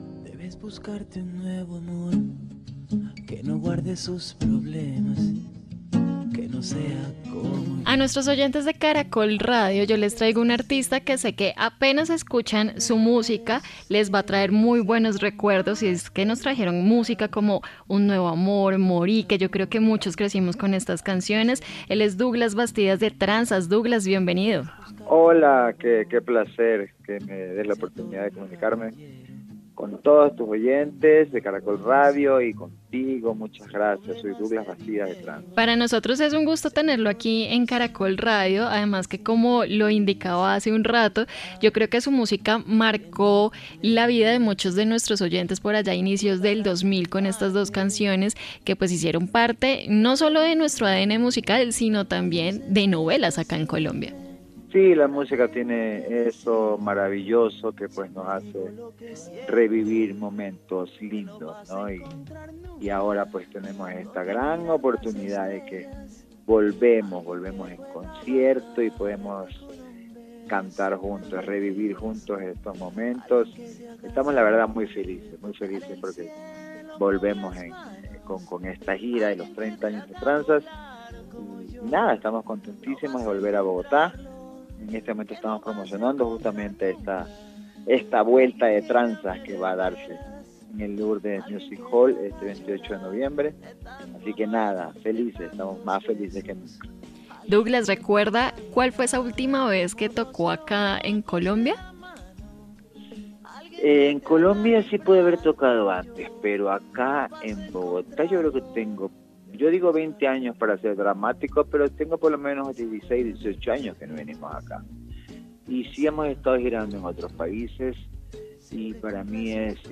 En entrevista con Caracol Radio, Douglas Bastidas, vocalista de la banda aseguró que: “Hace más de 18 años que nosotros no cantamos en Colombia y nos emociona regresar. Gracias a Colombia nosotros nos hicimos internacionales y nos empezaron a escuchar en otros países como Estados Unidos”, recordó el artista.